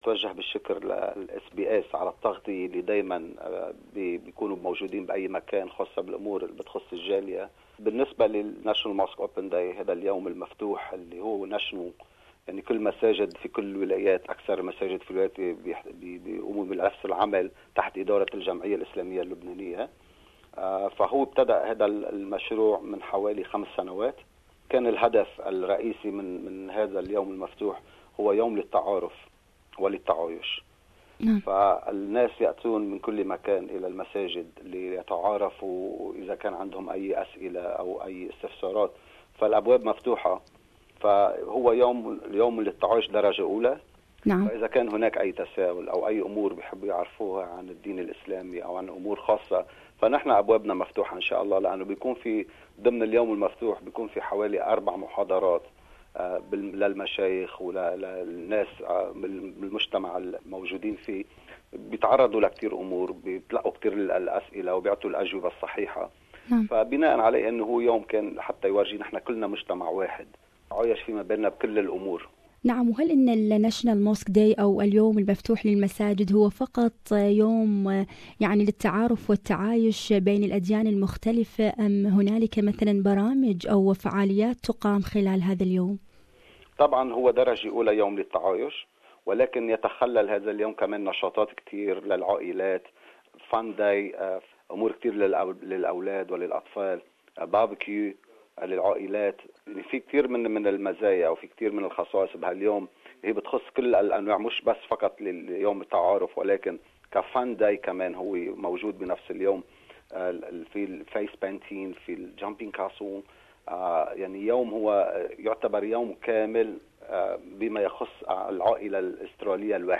Lebanese Muslim Association will hold a national Mosque Day in Australia on Saturday 28 of October. More on this, listen to this interview